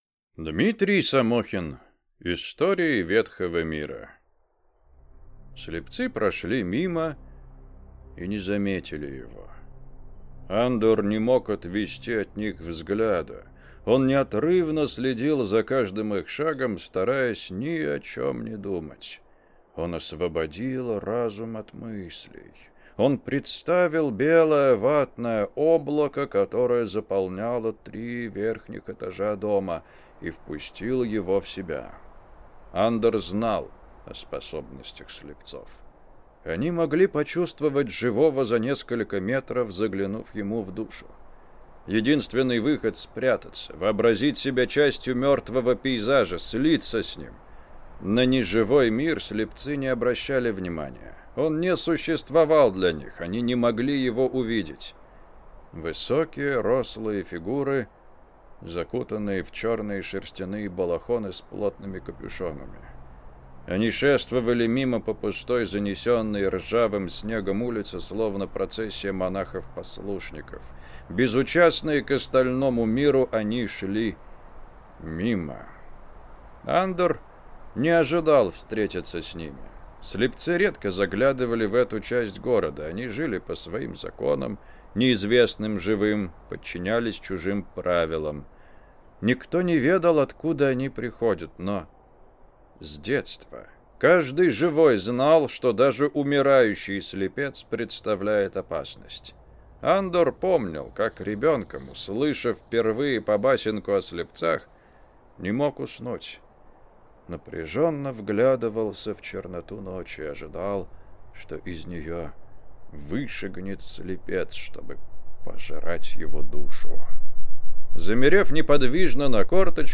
Аудиокнига Истории ветхого мира | Библиотека аудиокниг
Прослушать и бесплатно скачать фрагмент аудиокниги